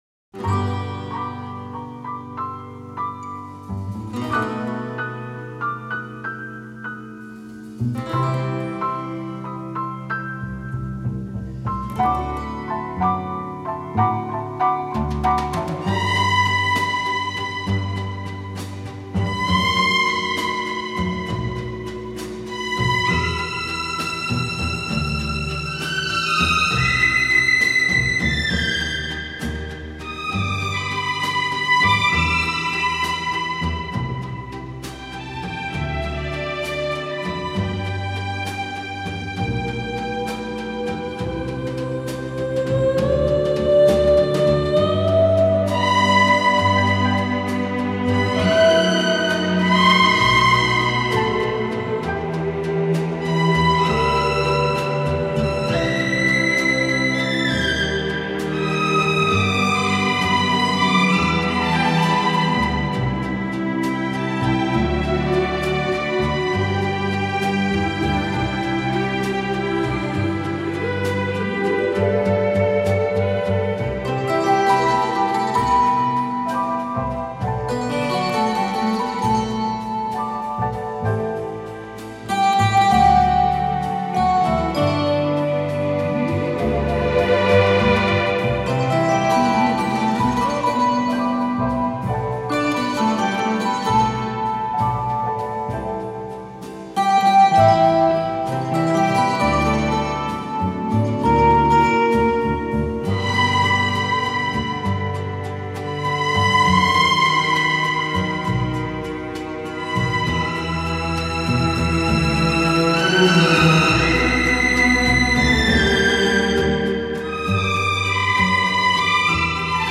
موسیقی بیکلام
آهنگ بی کلام
در این آهنگ سازهای مختلفی از جمله پیانو و ویولن کار شده است.